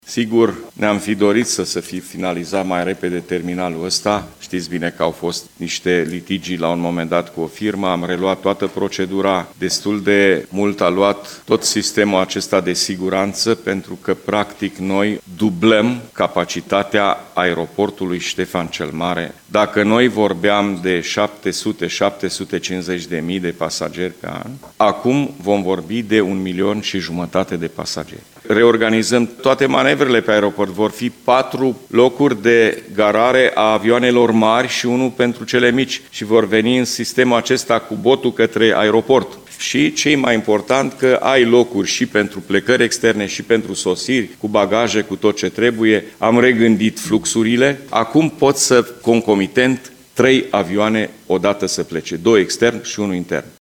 Președintele Consiliului Județean Suceava GHEORGHE FLUTUR a declarat astăzi că noul terminal va permite dublarea numărului de pasageri procesați.